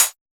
RDM_TapeA_SY1-HfHat.wav